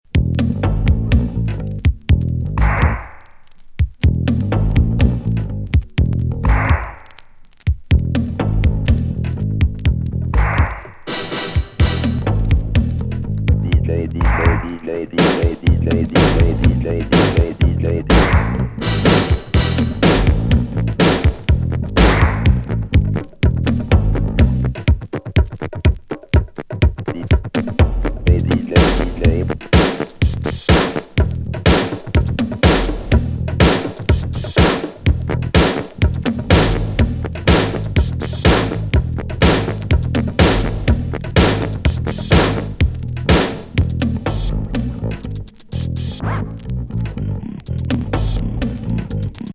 日本語シャウトなスロー・